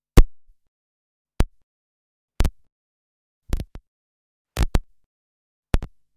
Звуки аудио-колонки
Шум возникновения тока в динамиках